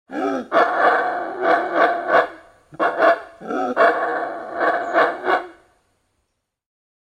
Monkey Crying And Grunting: Instant Play Sound Effect Button